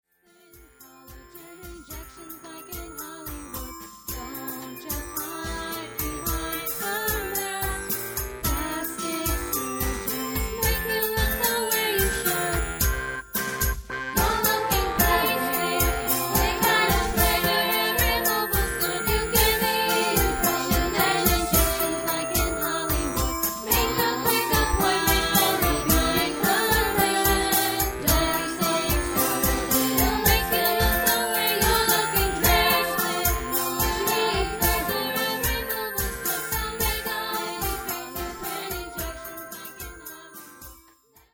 Sample from the Backing CD